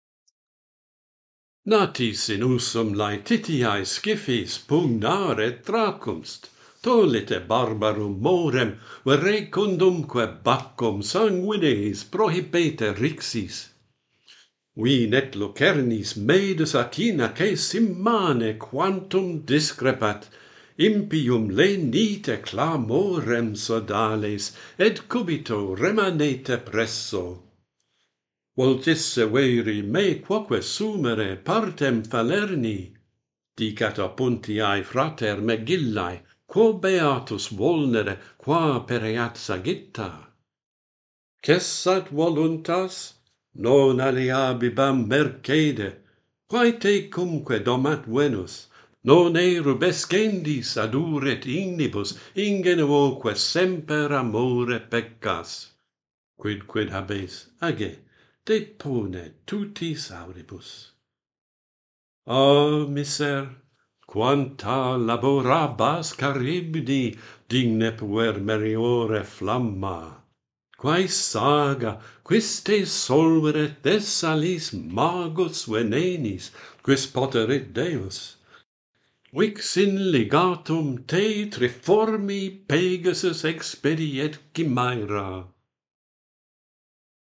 This dramatic monologue is a purely literary exercise: with the exception of the Falernian wine, the names and atmosphere are more Greek than Roman. The metre is Alcaics.